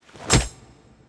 shared_hitreact_critical_withImpact.wav